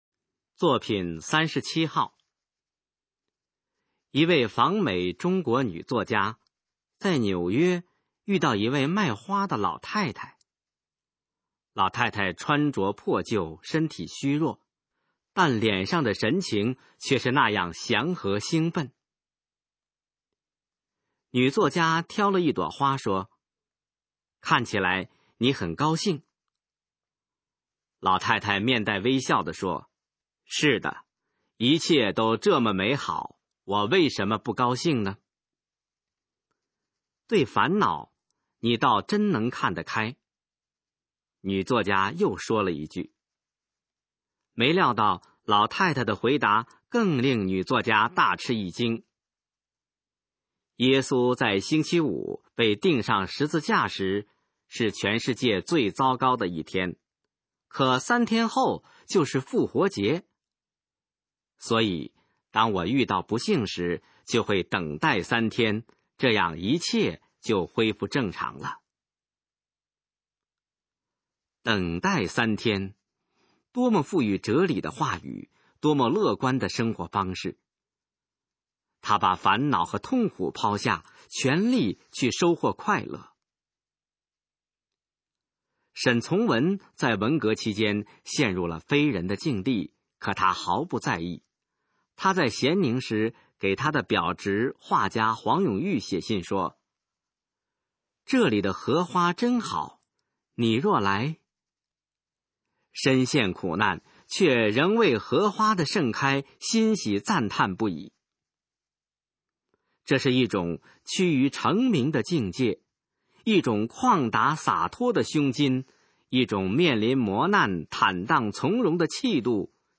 首页 视听 学说普通话 作品朗读（新大纲）
《态度创造快乐》示范朗读_水平测试（等级考试）用60篇朗读作品范读